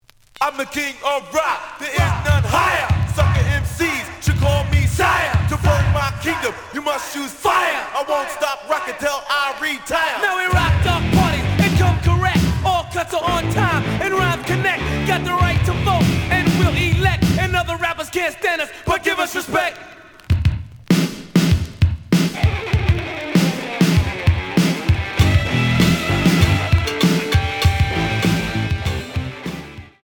The audio sample is recorded from the actual item.
●Format: 7 inch
●Genre: Hip Hop / R&B